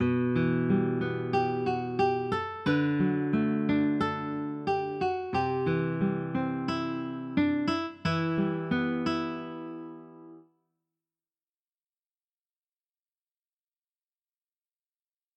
ギター がね。すごくいい。